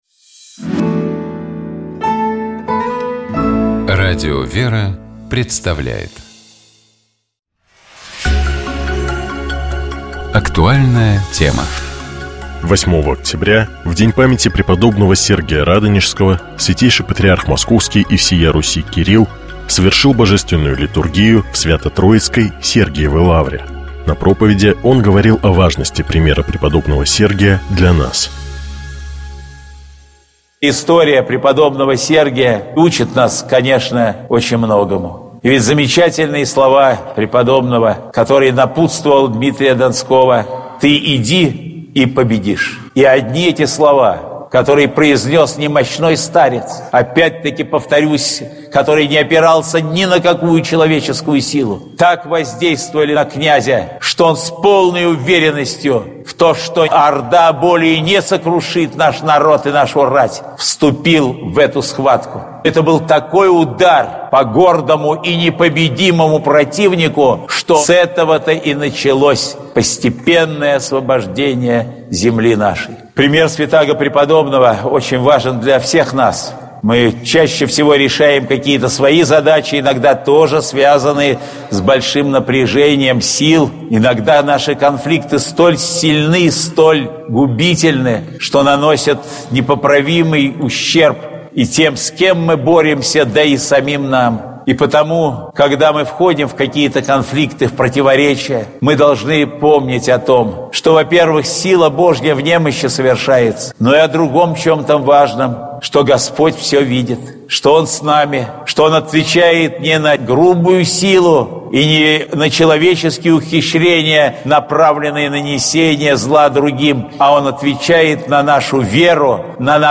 8 октября в День памяти преподобного Сергия Радонежского Святейший Патриарх Московский и всея Руси Кирилл совершил Божественную литургию в Свято-Троицкой Сергиевой лавре.
На проповеди он говорил о важности примера преподобного Сергия для нас: